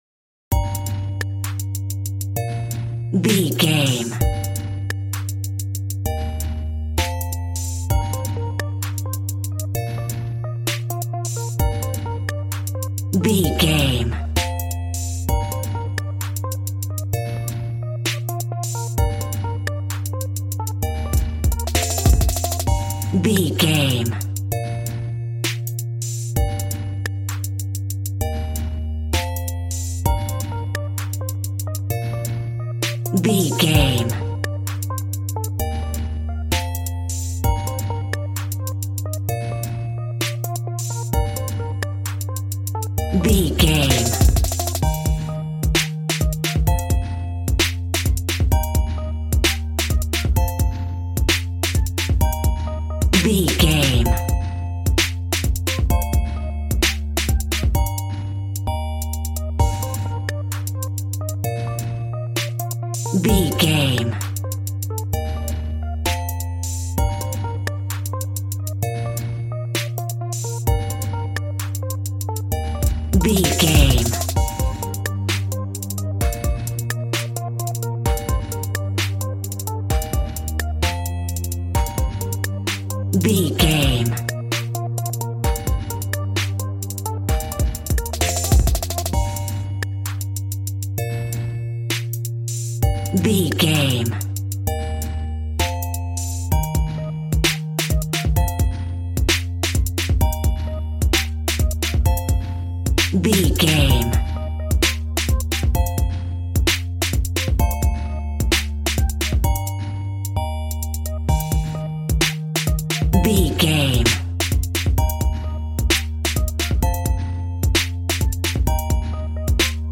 Aeolian/Minor
Fast
groovy
synthesiser
drums
piano